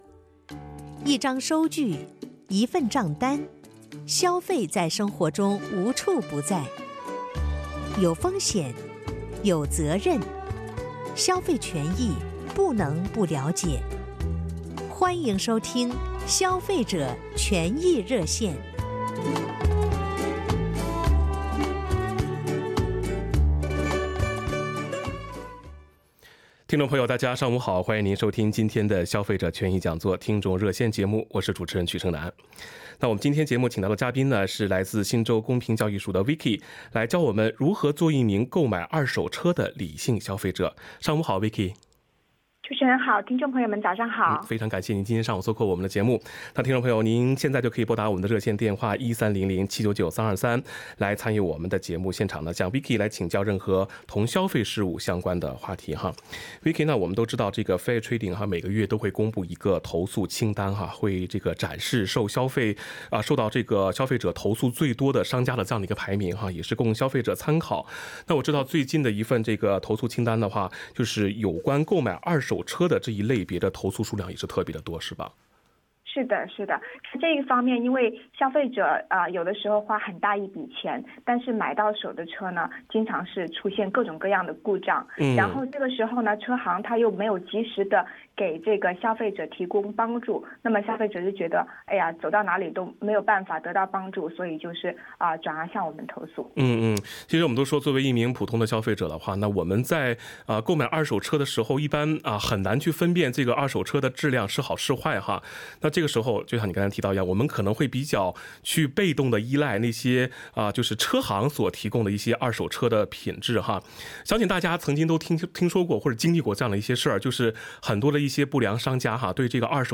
本期《消费者权益讲座》听众热线节目